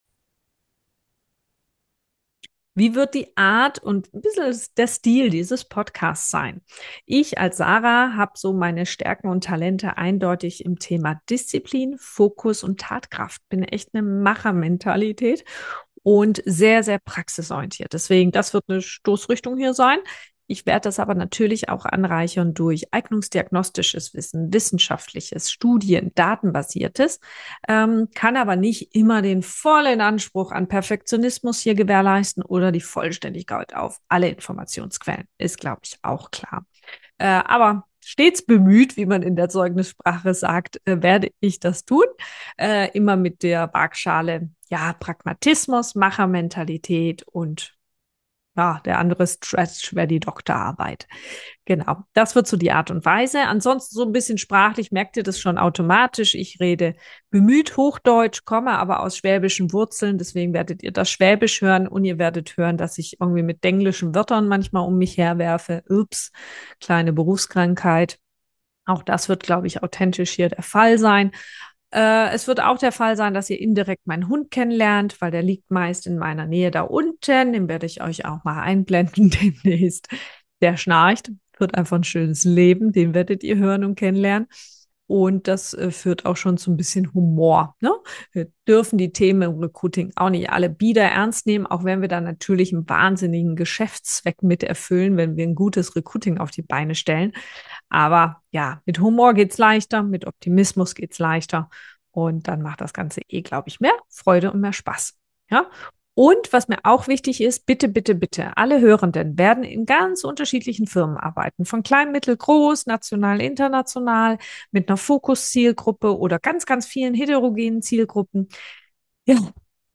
und ja, mein Hund schnarcht im Hintergrund ;-) Recruiting muss